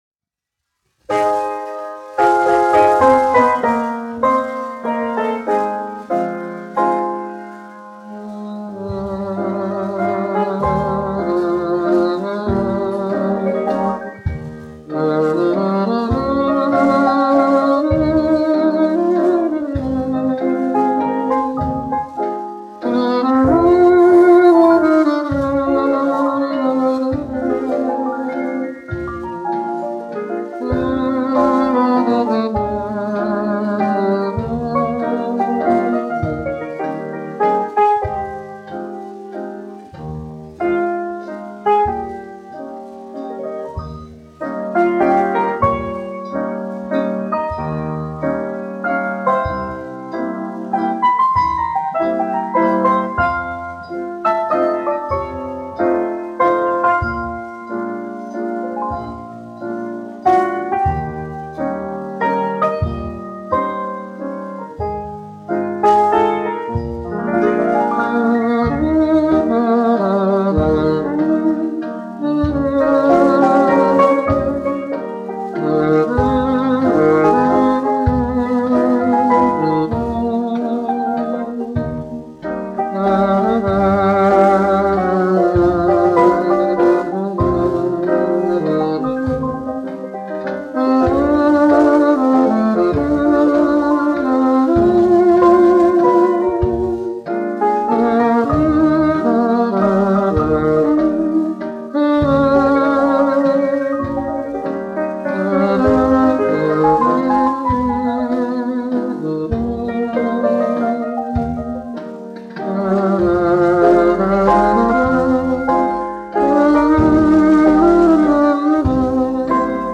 1 skpl. : analogs, 78 apgr/min, mono ; 25 cm
Populārā instrumentālā mūzika
Valši
Latvijas vēsturiskie šellaka skaņuplašu ieraksti (Kolekcija)